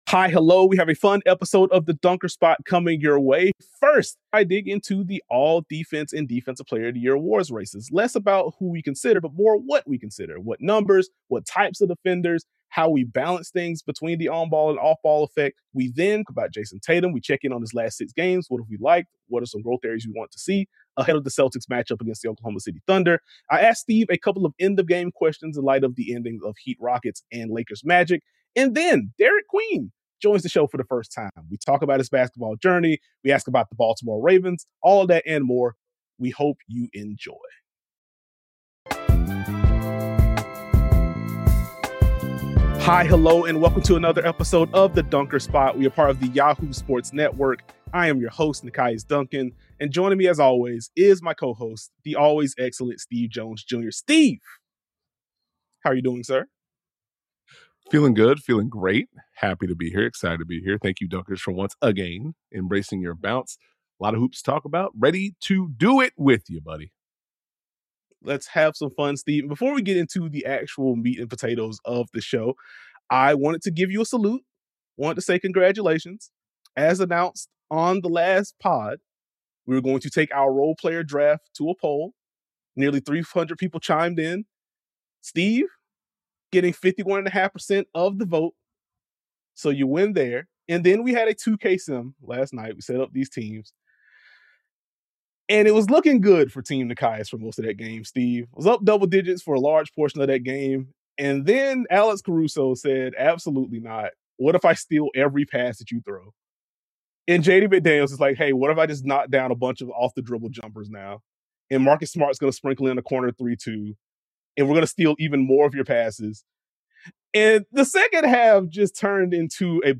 56:22 Derik Queen interview